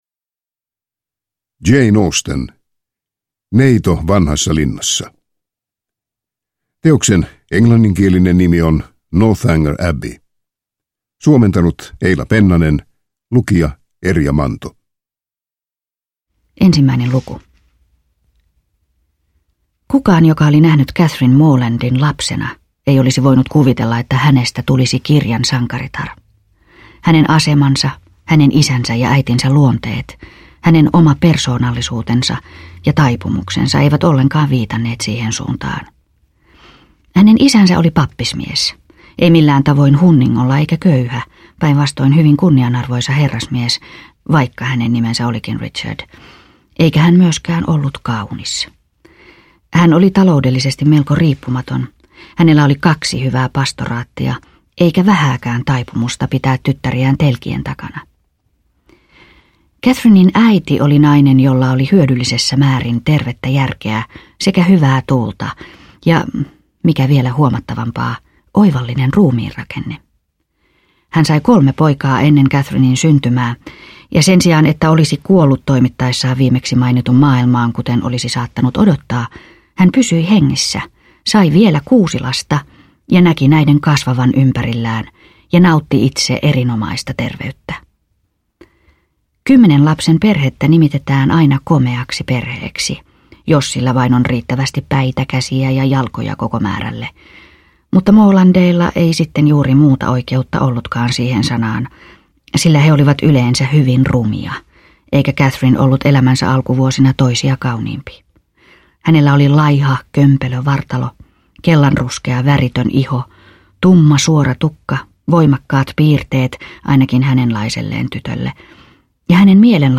Neito vanhassa linnassa – Ljudbok – Laddas ner
Uppläsare: